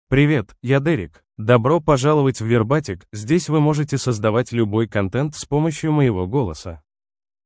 Derek — Male Russian AI voice
Voice sample
Male
Derek delivers clear pronunciation with authentic Russia Russian intonation, making your content sound professionally produced.